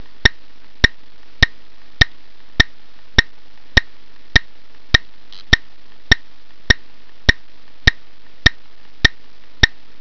リズムは四拍子。一拍の間に二音節発音します。
百拍の速度 で発音できれば百点です。
tempo100.wav